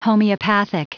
Prononciation du mot homeopathic en anglais (fichier audio)
Prononciation du mot : homeopathic